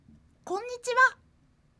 ボイス
ダウンロード 女性_「こんにちは」
大人女性挨拶